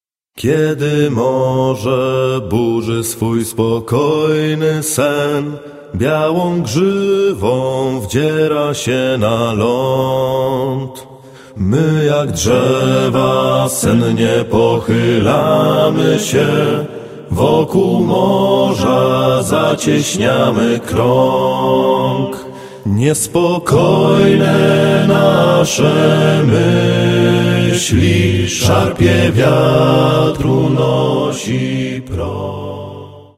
mel. trad.